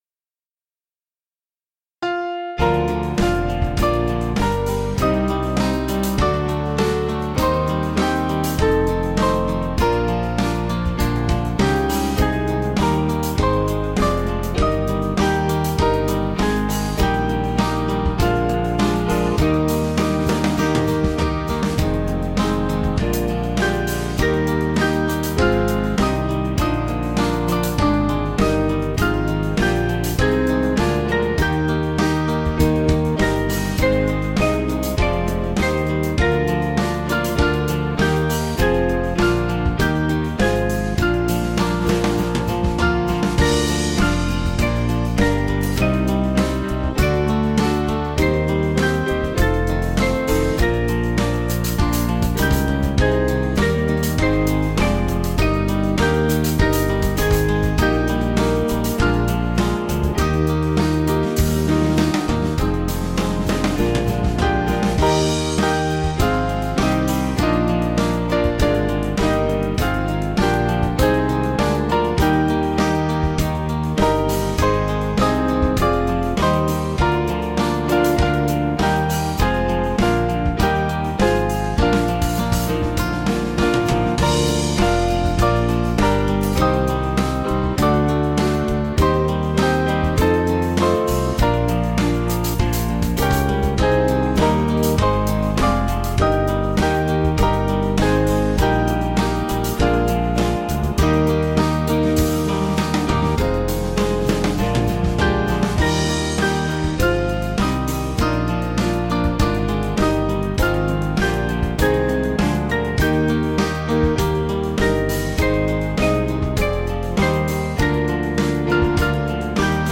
Small Band
(CM)   5/Eb 483.2kb